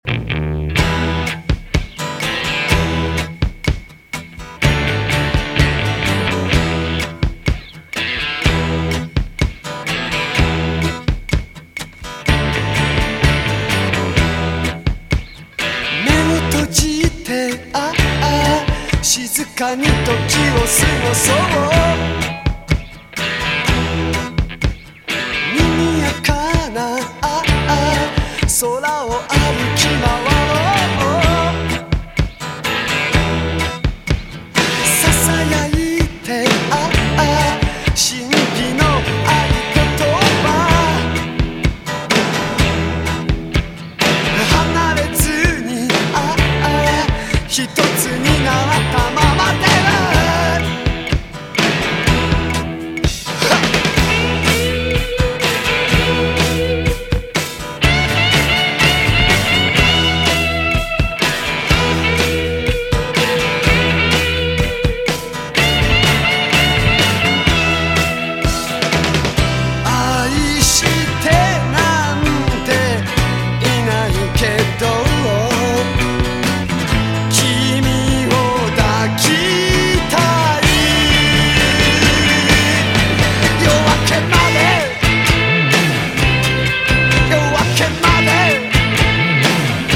DISCO / DANCE CLASSIC / JAPANESE DISCO / CITY POP
アーバン・メロウなこみ上げレディ・ソウル！